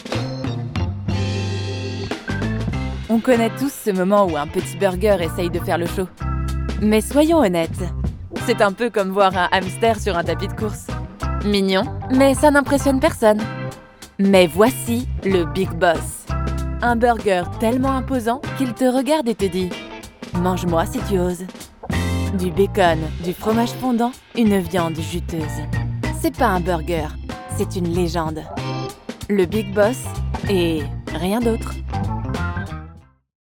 Jong, Natuurlijk, Vriendelijk, Zakelijk
Commercieel